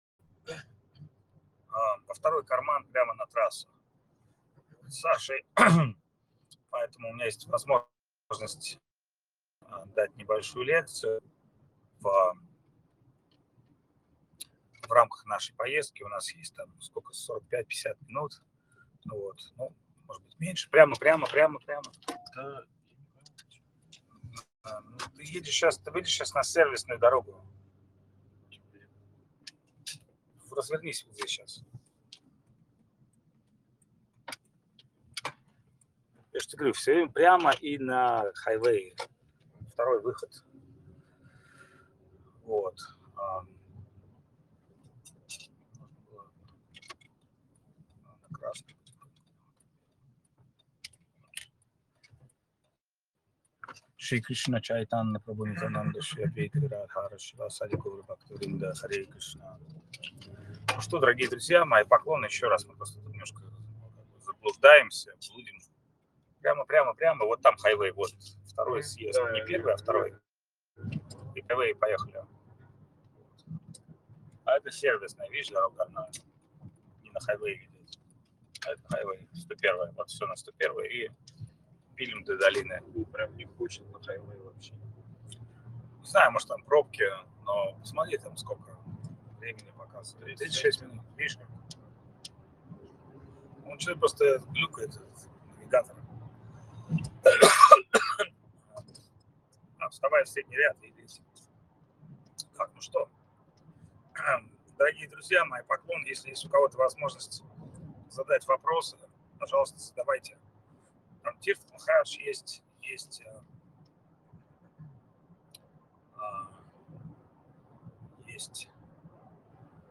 Калифорния, США
Лекции полностью